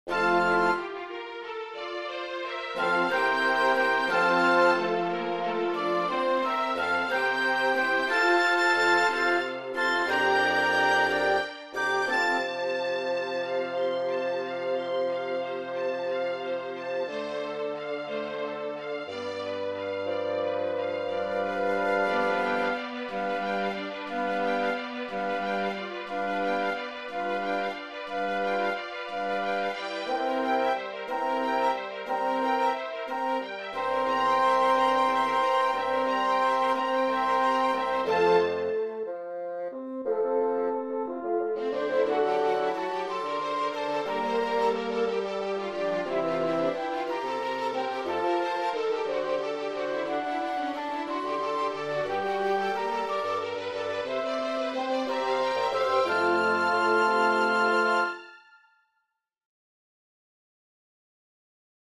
Arranging Symphonic, Piano, and Vocal Works for Performance on Carillon
mm 87-91, descending triplet theme, this time accent is on downbeat, phrasing reflects that.
Put 8th notes in triplet rhythm on top, with dissonances pushing to resolution. Theme in middle layer and even bass layer.
Put theme on high range, supporting harmony and rhythm in middle or bass, but 8ths catching essential notes instead of running chromatic 16ths.